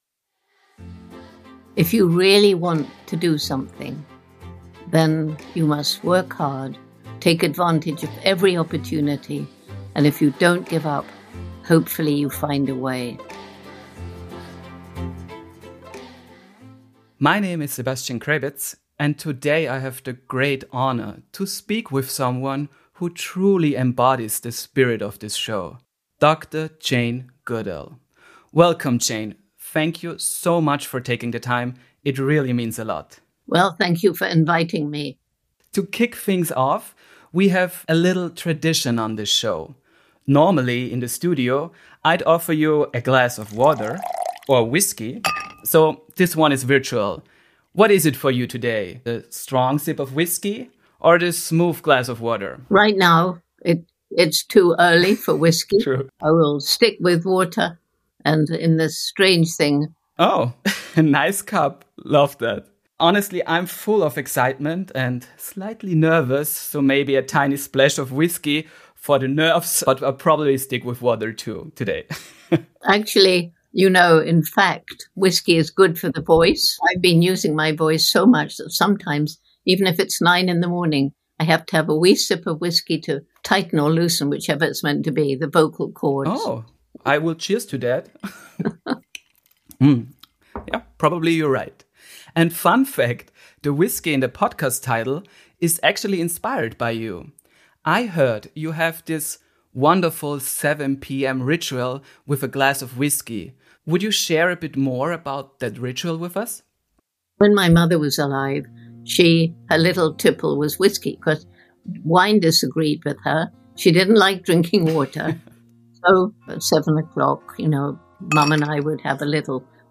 In dieser Folge von Whiskey oder Wasser hörst du das komplette Gespräch mit Dr. Jane Goodall – in voller Länge, im englischen Original, so wie es aufgenommen wurde.